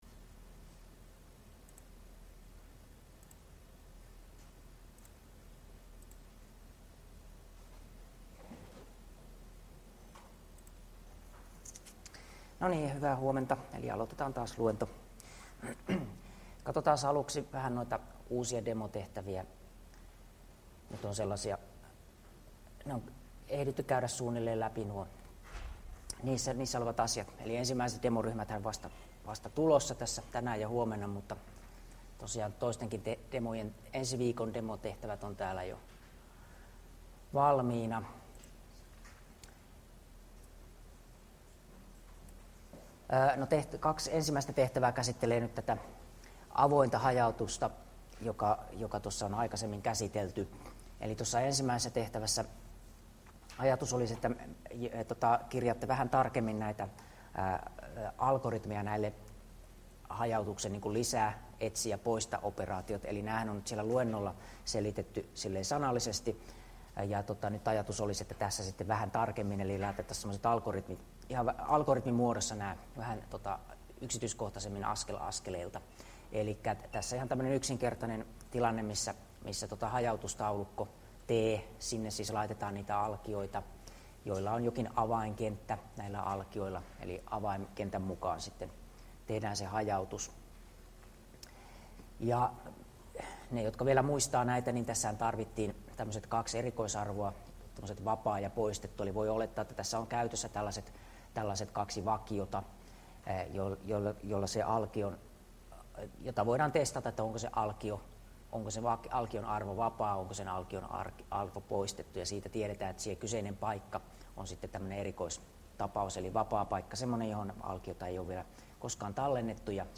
Luento 6 — Moniviestin